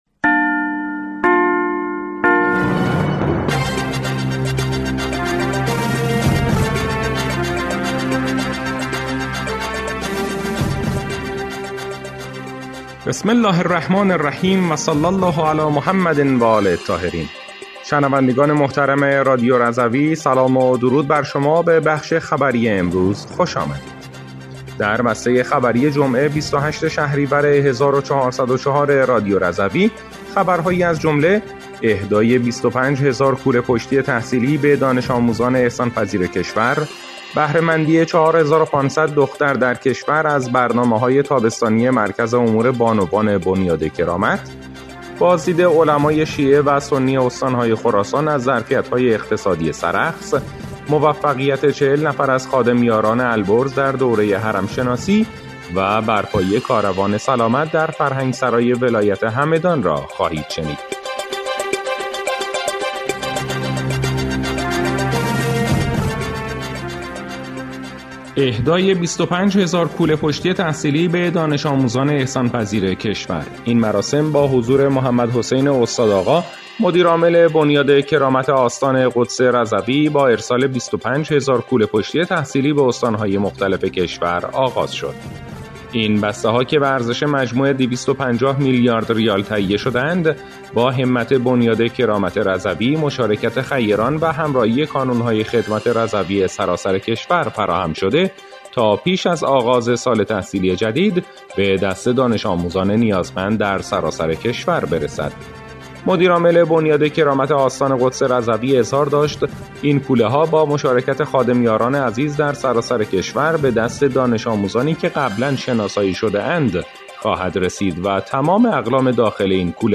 بسته خبری ۲۸ شهریور ۱۴۰۴ رادیو رضوی/